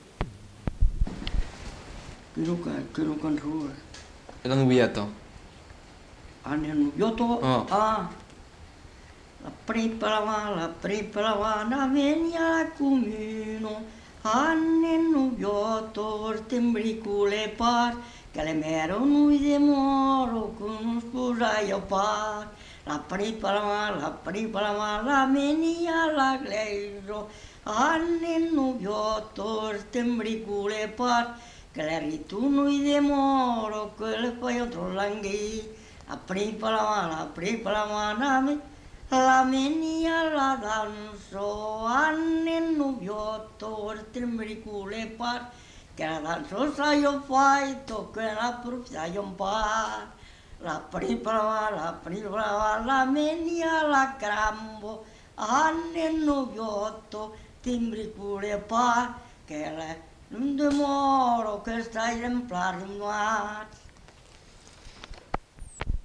Genre : chant
Effectif : 1
Type de voix : voix d'homme
Production du son : chanté